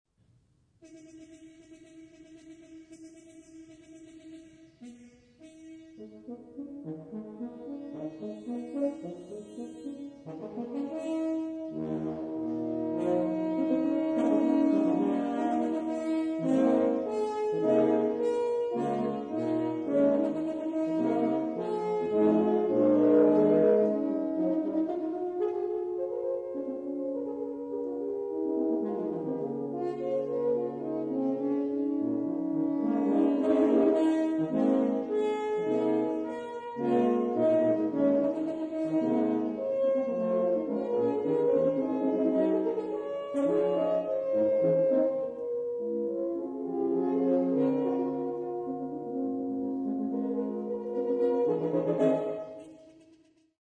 short suite in three movements
Quartetto di Corni Horn quartet